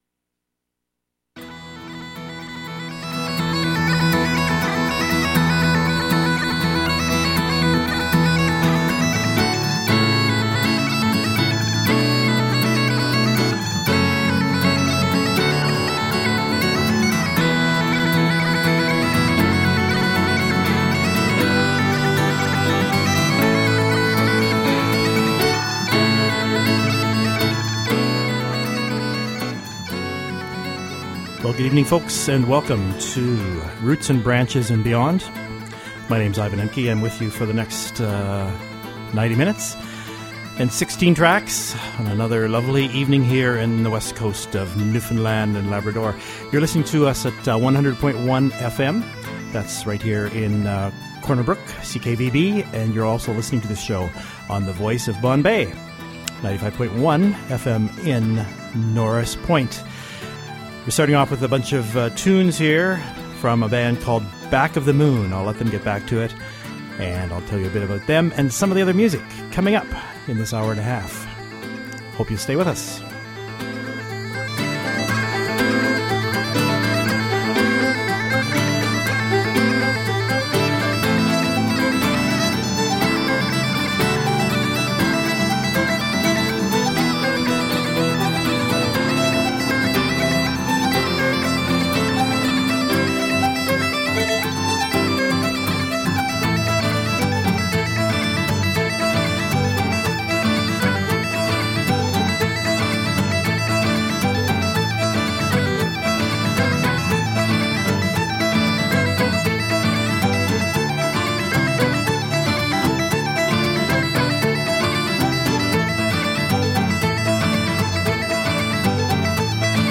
Slow tunes and pirate songs